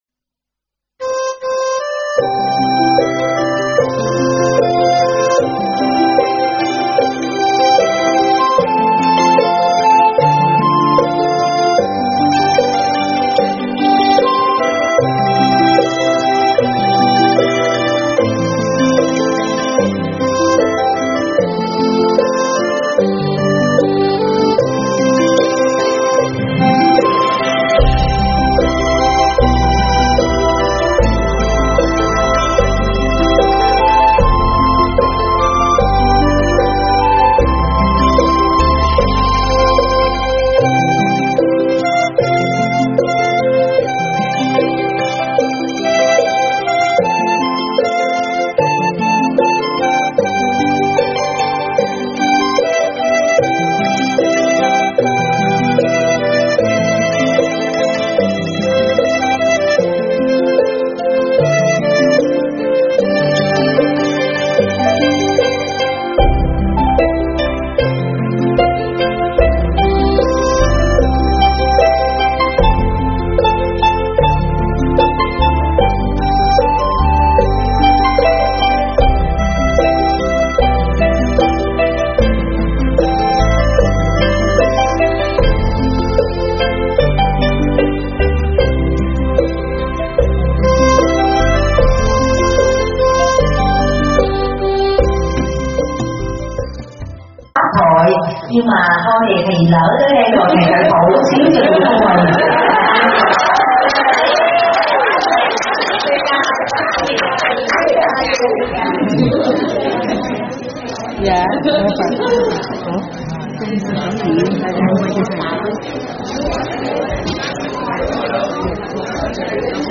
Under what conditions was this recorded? giảng tại Chùa A Di Đà, Virginia